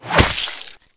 monsterclaw.ogg